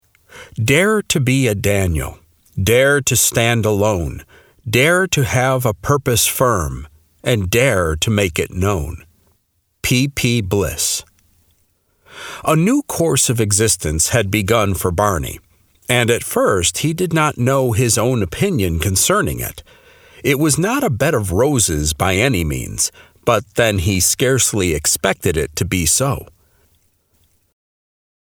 Audiobook: Drawn Together Under Fire - MP3 download - Lamplighter Ministries
Drawn-Together-Audiobook-Sample.mp3